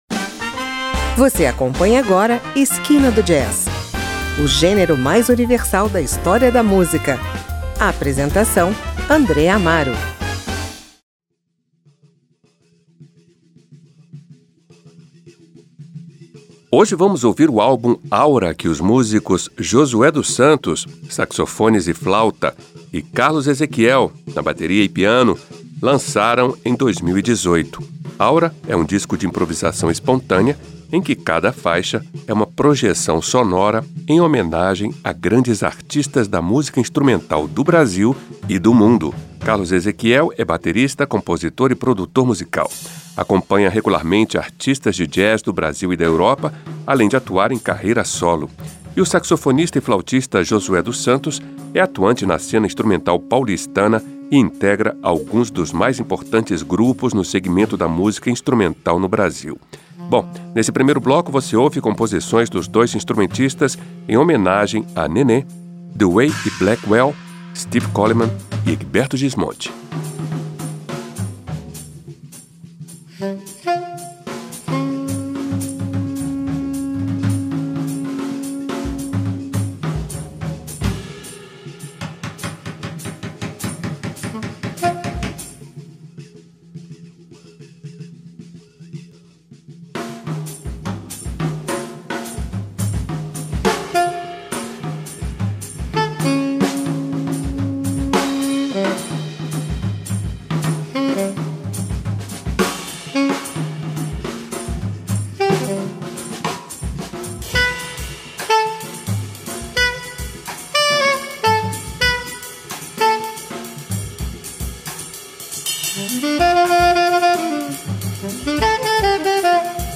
saxofones e flauta
bateria e piano